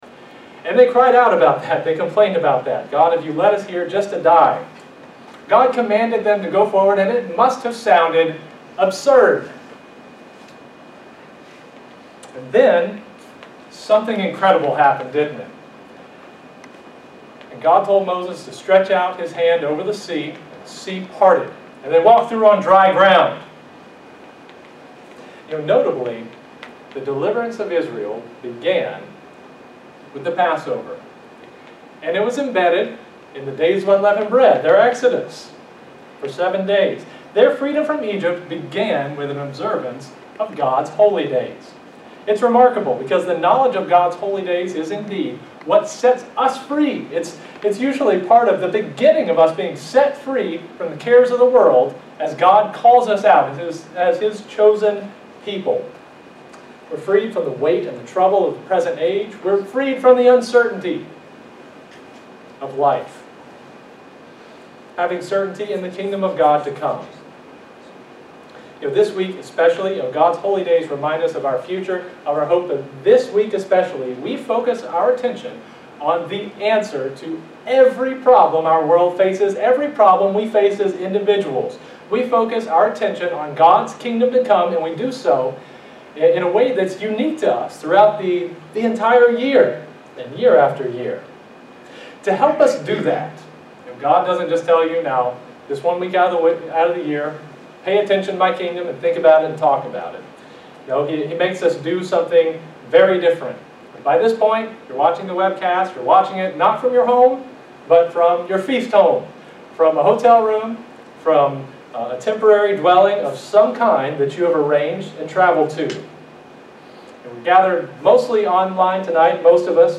This sermon was given at the Lancaster, Pennsylvania 2020 Feast site.